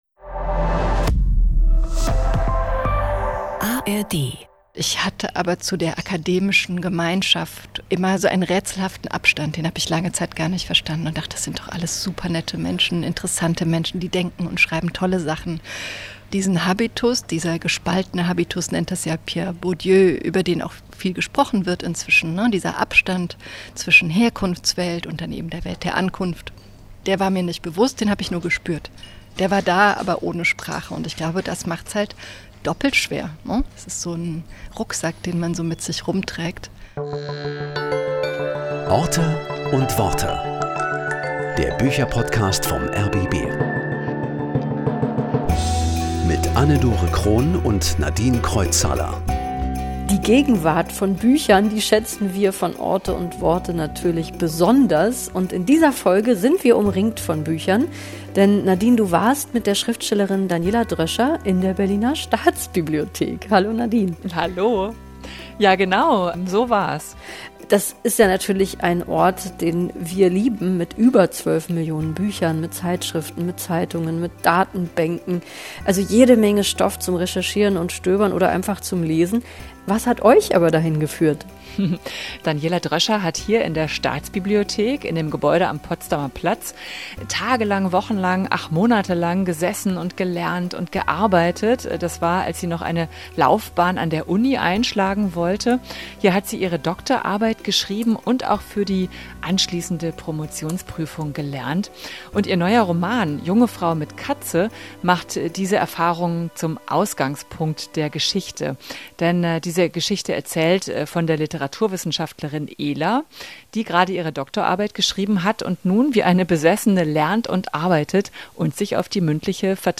treffen sich in der Berliner Staatsbibliothek